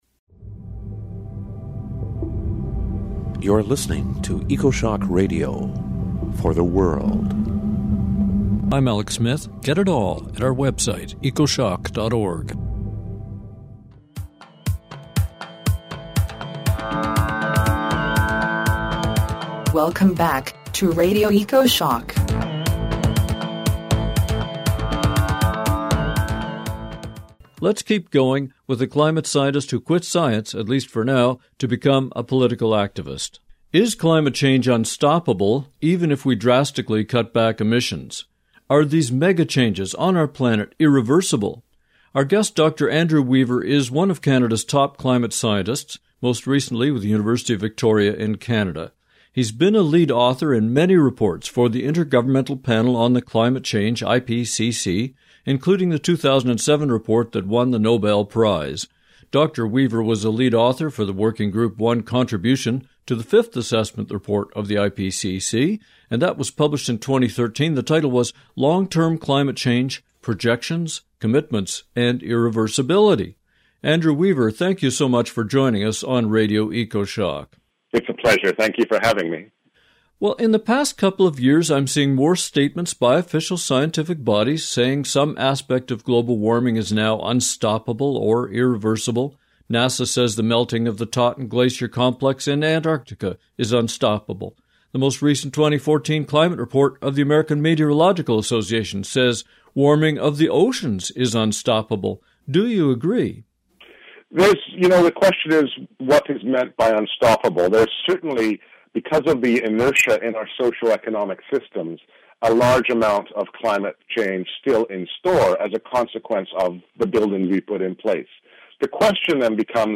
We’ll follow up with a chat with one of Canada’s top climate scientists. He says we don’t need more science, we need action to save ourselves.